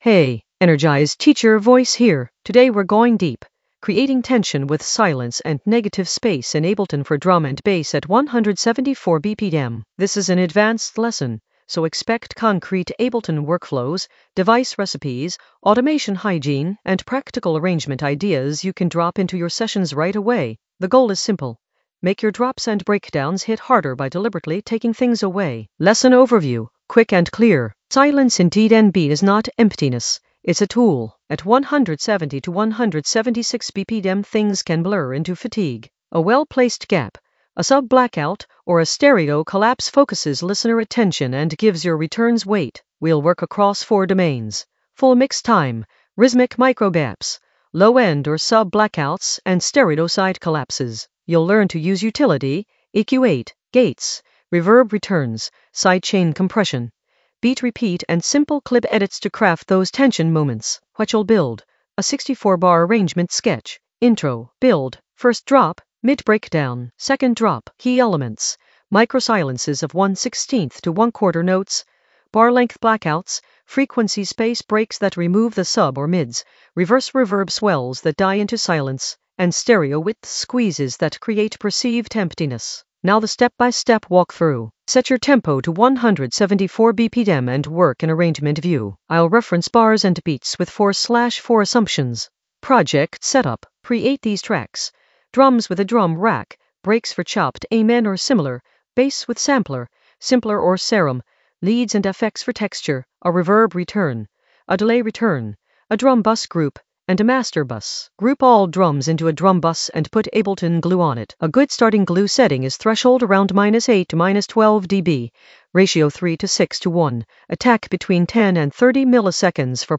An AI-generated advanced Ableton lesson focused on Creating tension with silence and negative space in the Arrangement area of drum and bass production.
Narrated lesson audio
The voice track includes the tutorial plus extra teacher commentary.